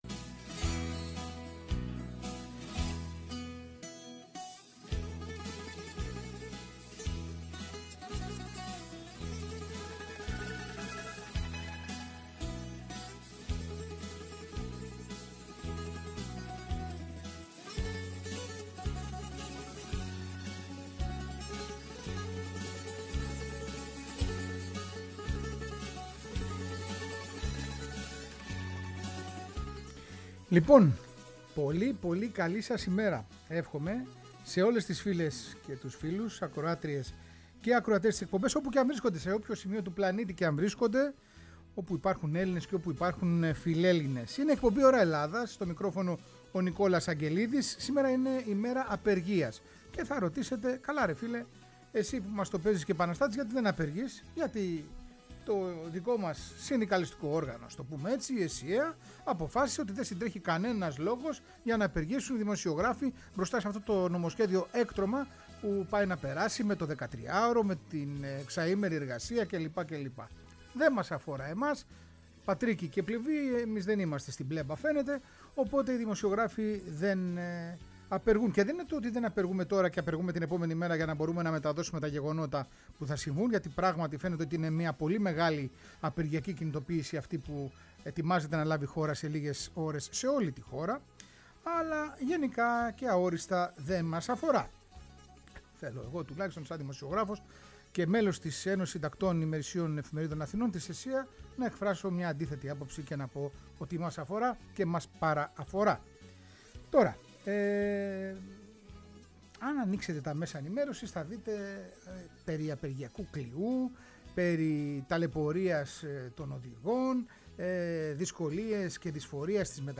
Συνεντεύξεις